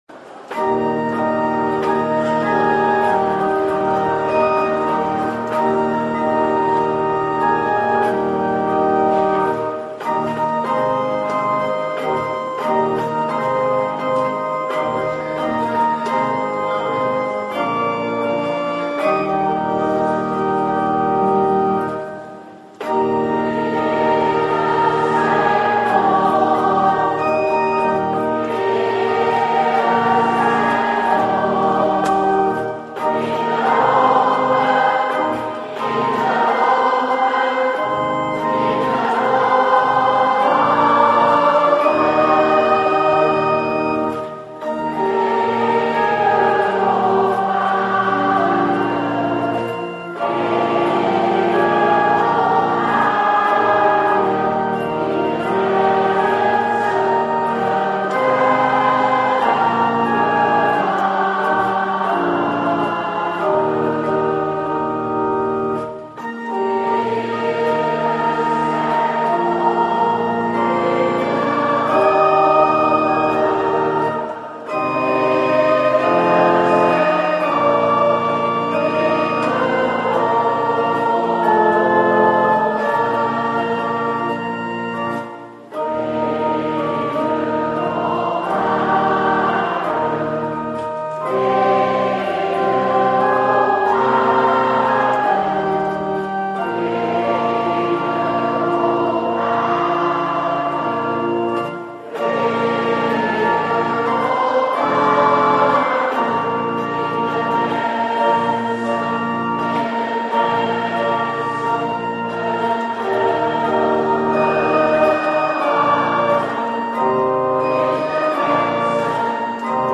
158-Ere zij God-Orgel-grote kerk.mp3